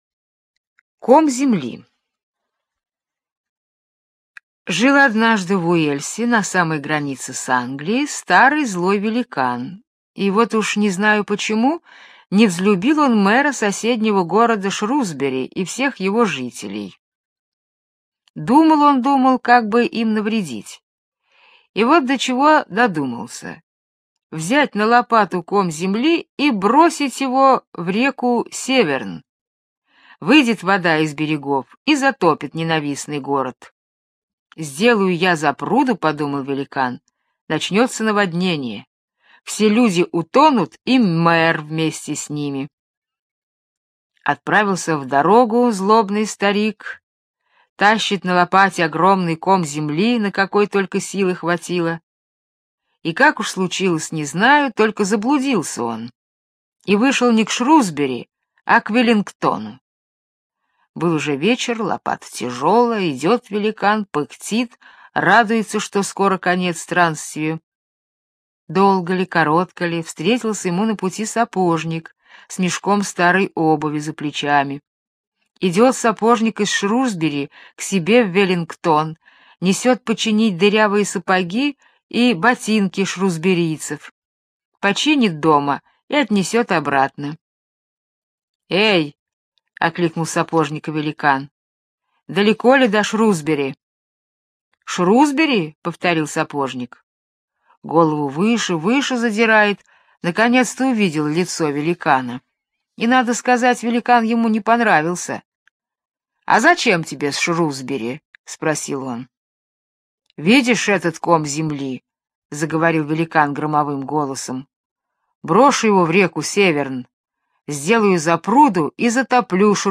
Ком земли - британская аудиосказка - слушать онлайн